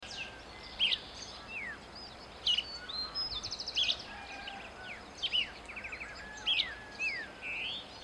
Bran-colored Flycatcher (Myiophobus fasciatus)
Life Stage: Adult
Condition: Wild
Certainty: Photographed, Recorded vocal